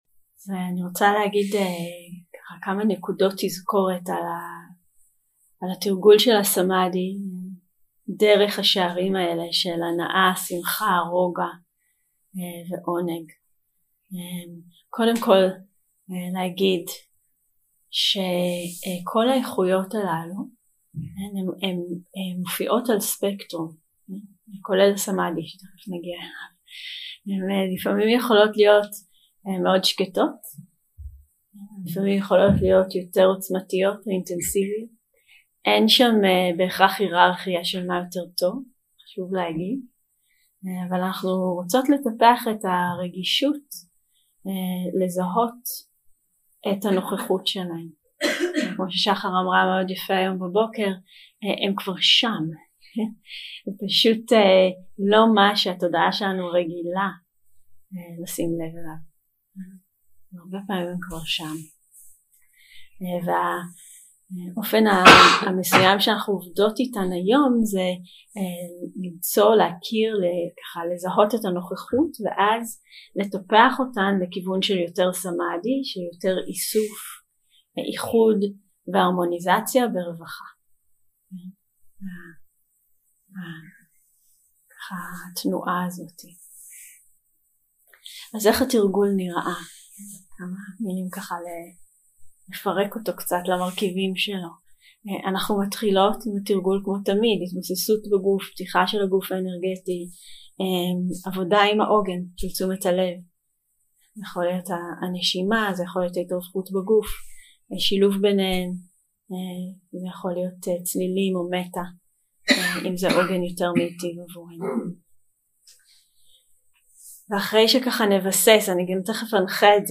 יום 4 – הקלטה 8 – צהריים – הנחיות למדיטציה – הנחיות לסמאדהי 0:00 0:00 סוג ההקלטה: Dharma type: Guided meditation שפת ההקלטה: Dharma talk language: Hebrew